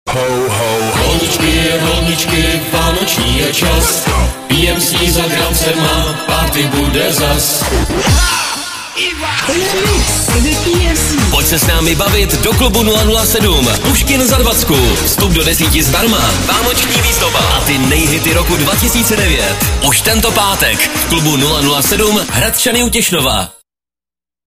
pravidelná taneční párty Freeradia 107 FM - energický mix s pořádnou porcí nejžhavějších hitů